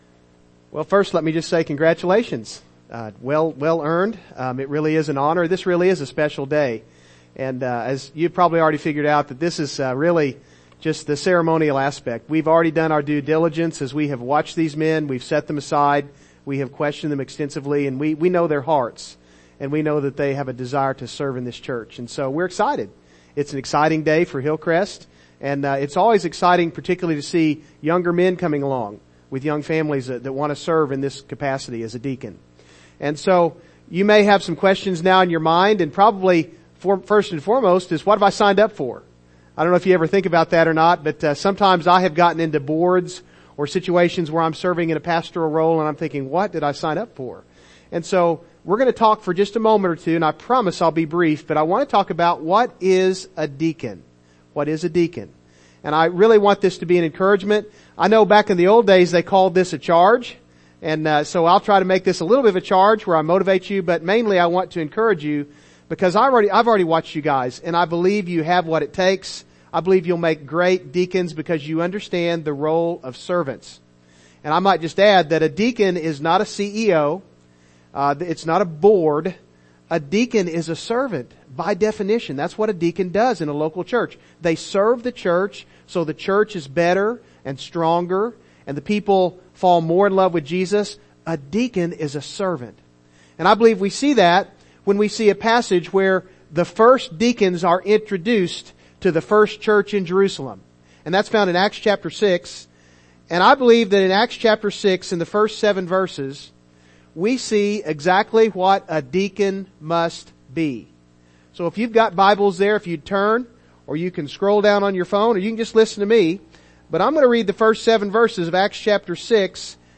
Deacon Ordination Service - Hillcrest Baptist Church • Lebanon, MO